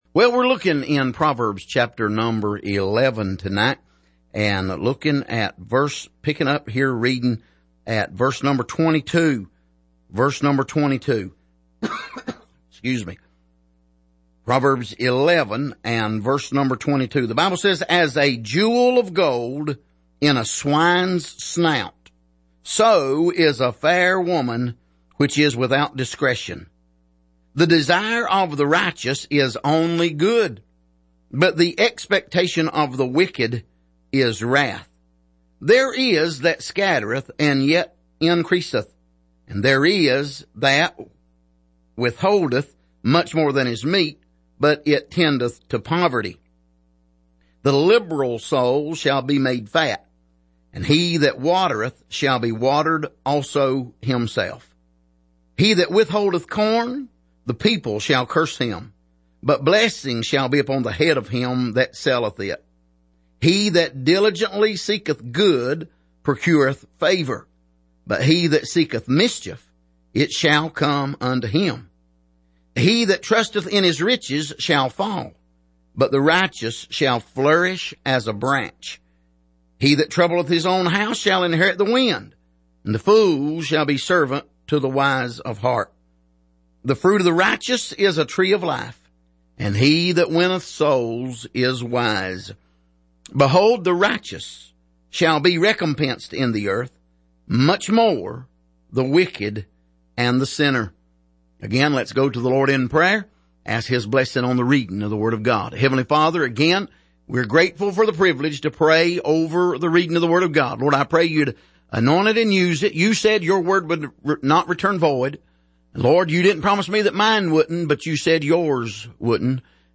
Passage: Proverbs 11:22-31 Service: Sunday Evening